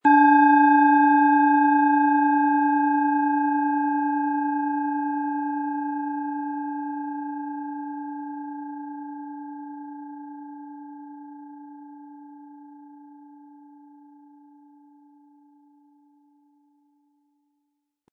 Planetenschale® Lebenskraft entfalten & Angeregt fühlen mit Mars, Ø 10,4 cm, 100-180 Gramm inkl. Klöppel
Wie klingt diese tibetische Klangschale mit dem Planetenton Mars?
Um den Original-Klang genau dieser Schale zu hören, lassen Sie bitte den hinterlegten Sound abspielen.
Der Schlegel lässt die Schale harmonisch und angenehm tönen.
HerstellungIn Handarbeit getrieben
MaterialBronze